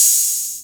Open Hats
OpHat (Maestro3).wav